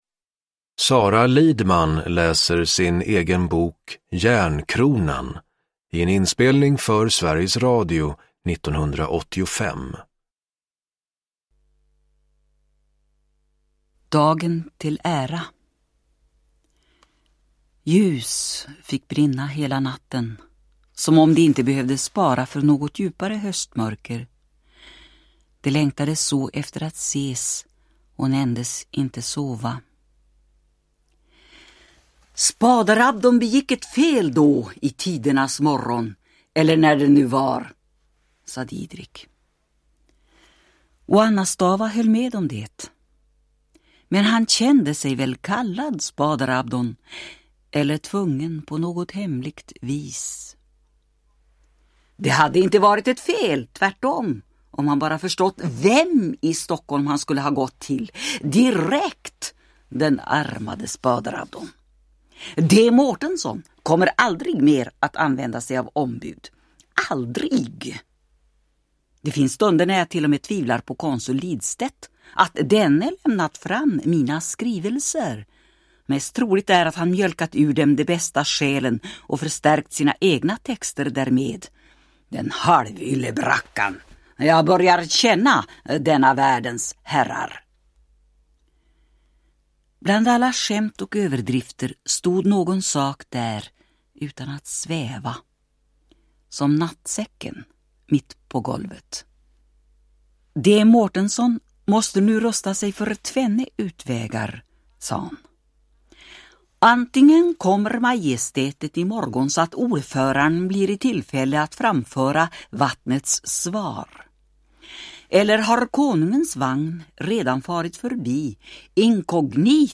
Uppläsare: Sara Lidman
Ljudbok
Utgiven i samarbete med Sveriges Radio.